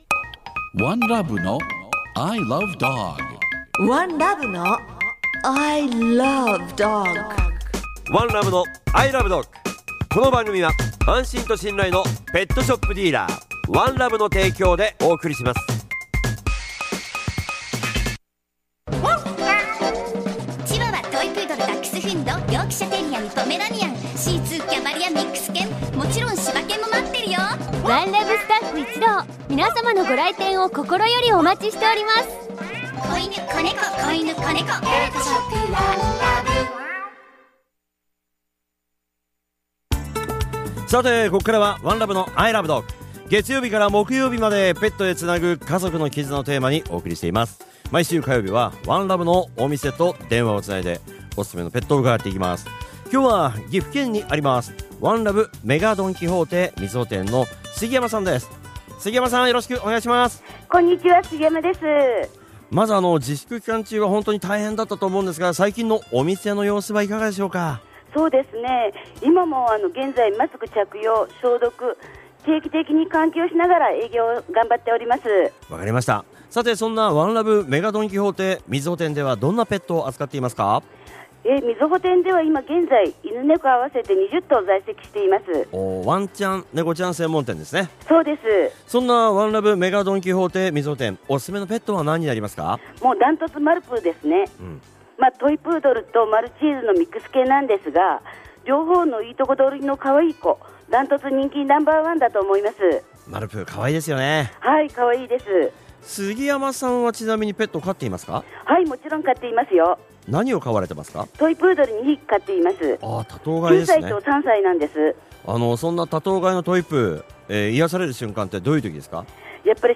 月曜は、街角突撃インタビューが聞けるワン！